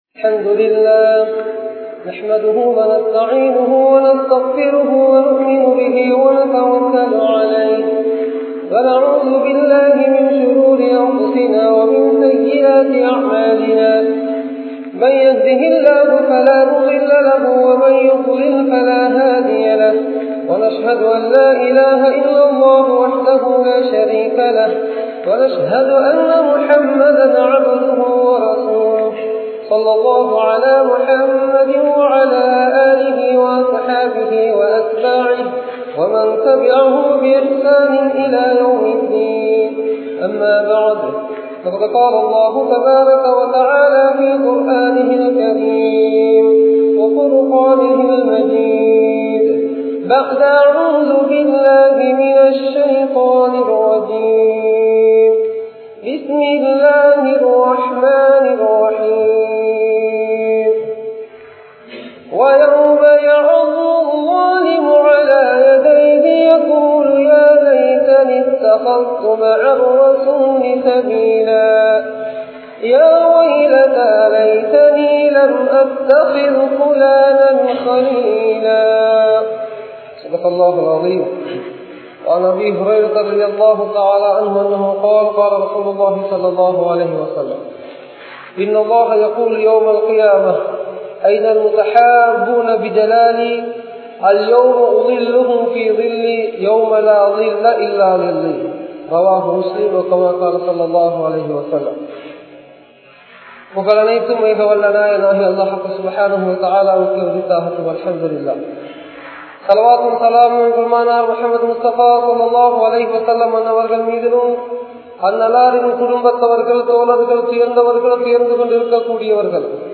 Islamiya Paarvaiel Nanparhal (இஸ்லாமிய பார்வையில் நண்பர்கள்) | Audio Bayans | All Ceylon Muslim Youth Community | Addalaichenai